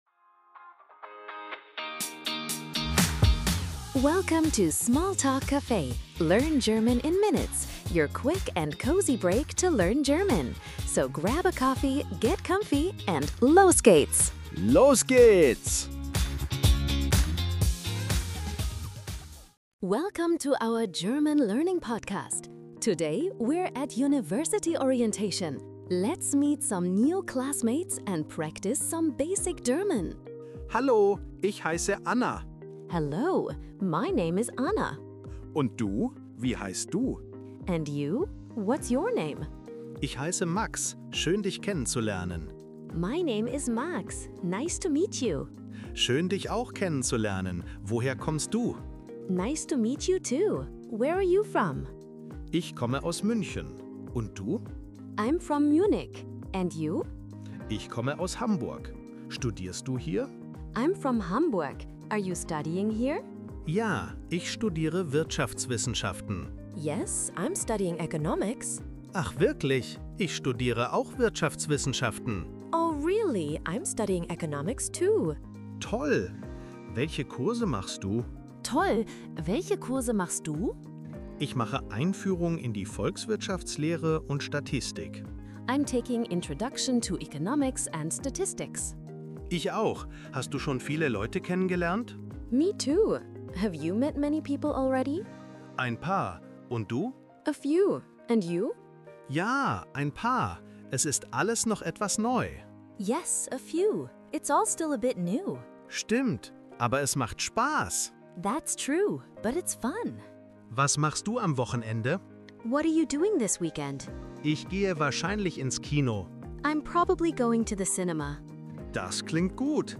Learn German with easy conversations.
Tune in for quick, real-life dialogues, helpful tips, and the confidence boost you need to navigate daily errands in German!